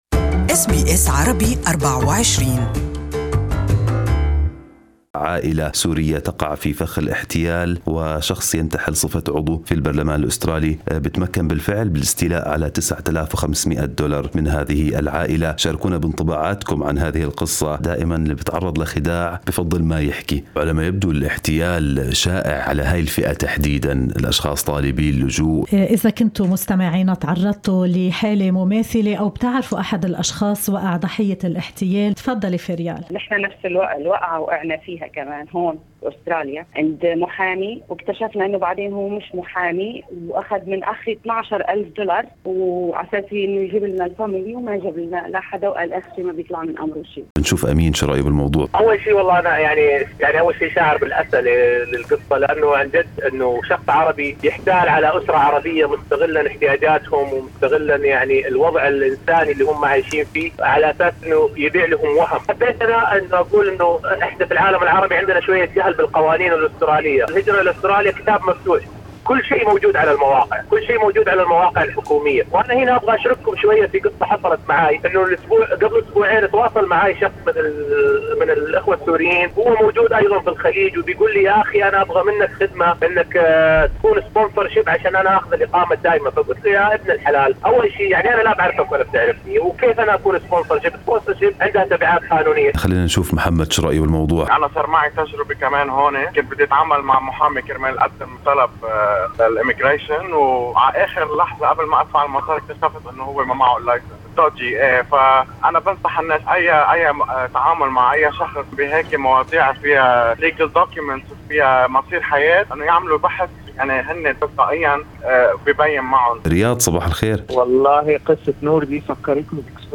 برنامج Good Morning Australia (يأتيكم كل صباح عبر أثير SBS Arabic24 من السادسة وحتى التاسعة صباحاً) طرح القضية للحوار المباشر وفُتح المجال أمام المستمعين لمشاركة انطباعاتهم عن قصة العائلة وما إذا كانوا قد تعرضوا لتجارب شبيهة في أستراليا.